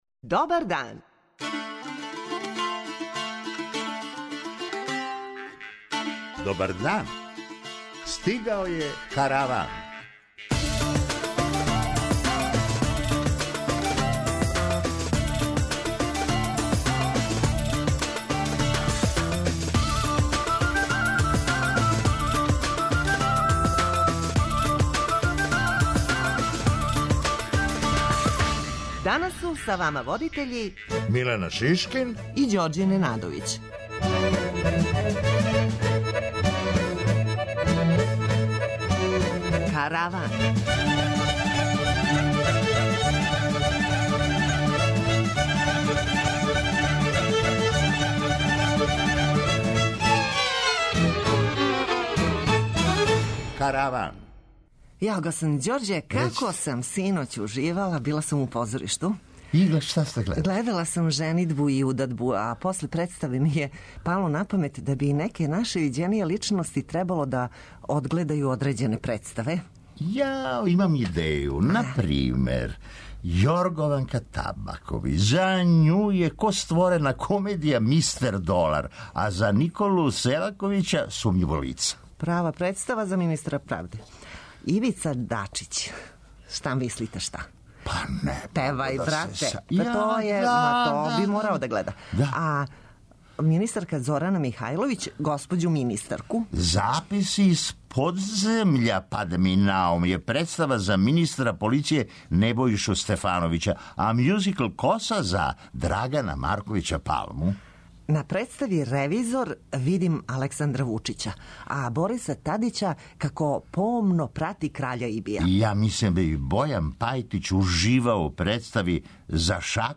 преузми : 23.88 MB Караван Autor: Забавна редакција Радио Бeограда 1 Караван се креће ка својој дестинацији већ више од 50 година, увек добро натоварен актуелним хумором и изворним народним песмама. [ детаљније ] Све епизоде серијала Аудио подкаст Радио Београд 1 Роми између вере, идентитета и промена Подстицаји у сточарству - шта доносе нове мере Ђорђе покорио Тирану Хумористичка емисија Хумористичка емисија